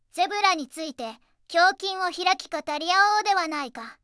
voicevox-voice-corpus / ROHAN-corpus /ずんだもん_ツンツン /ROHAN4600_0040.wav